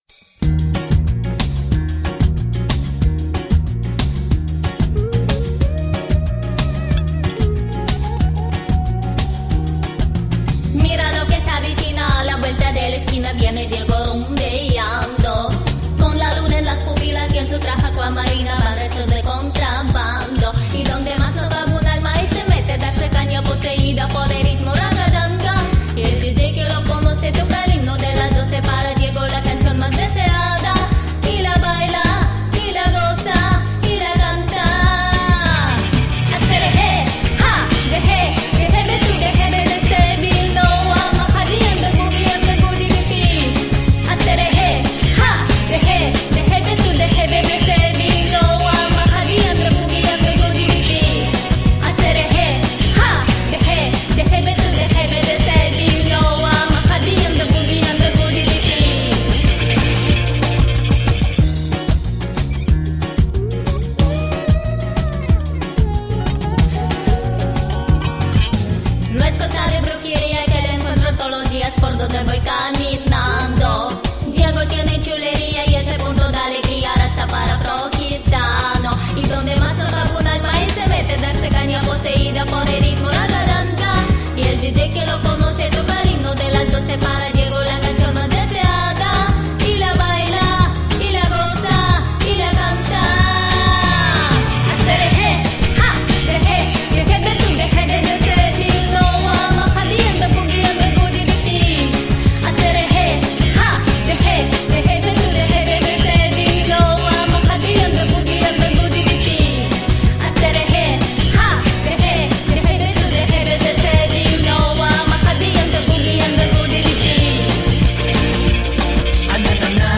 классный ринг! здоровские песни! отличные исполнения!)))))